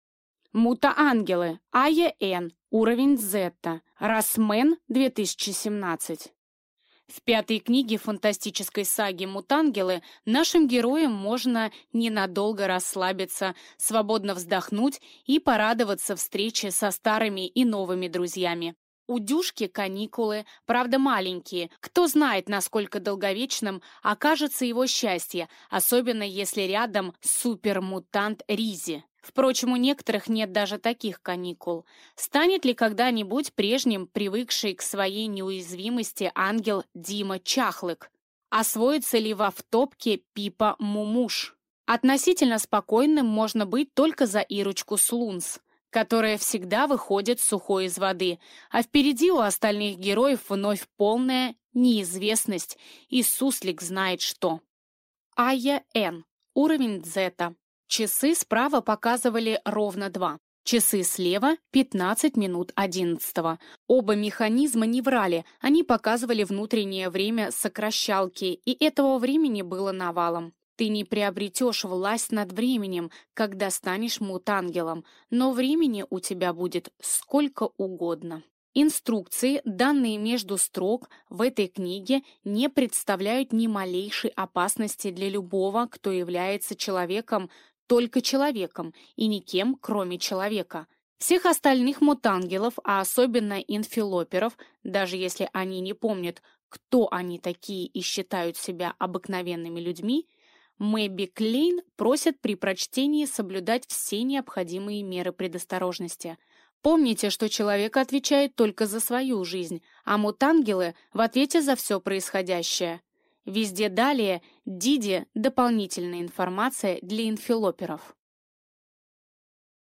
Аудиокнига Уровень дзета | Библиотека аудиокниг